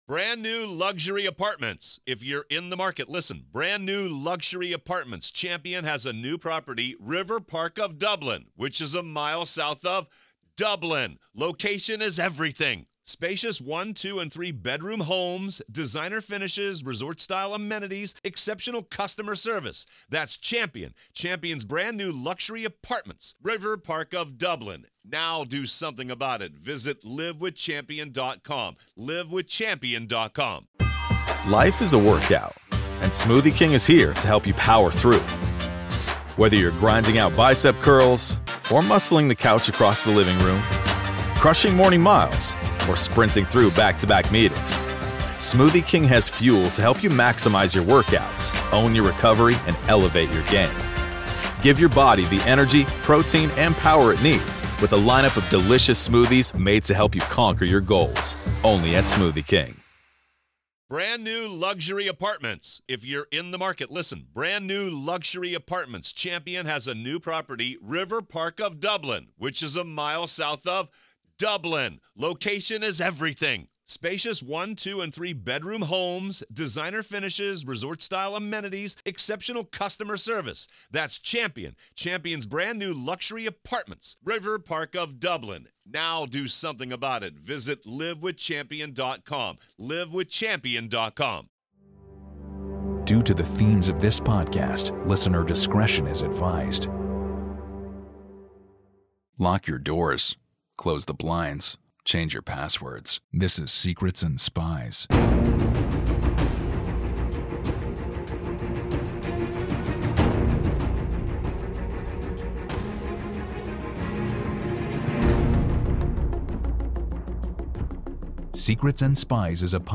For any fans of the spy genre, this is not an interview you want to miss.